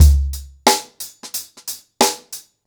TheStakeHouse-90BPM.7.wav